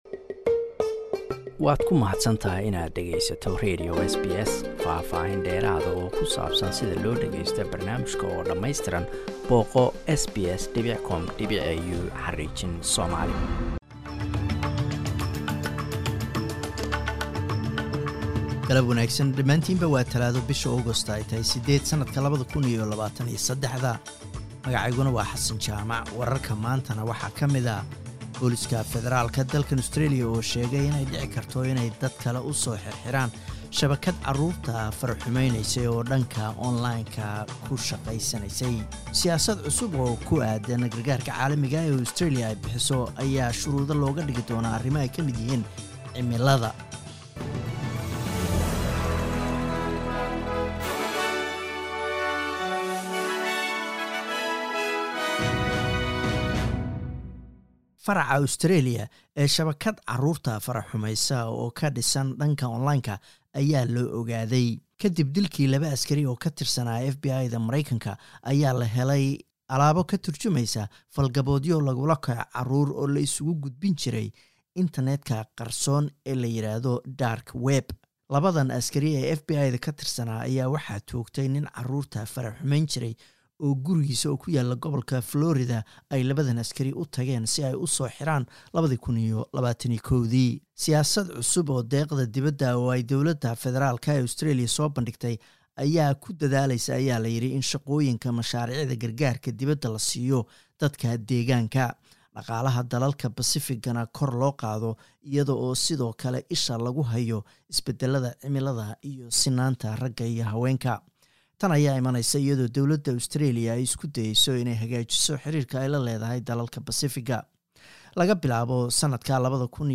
Wararka Australia iyo caalamka.